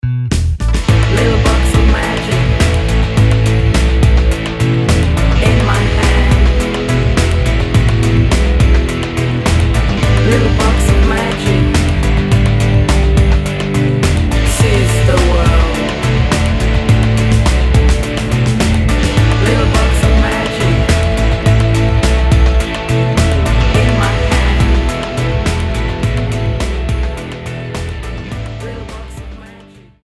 The results cohere into a sonic juggernaut.